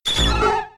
Cri de Ptitard K.O. dans Pokémon X et Y.